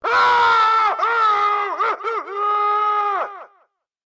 Free Horror sound effect: Male Scream.
Male Scream
yt_WMFyVey_arU_male_scream.mp3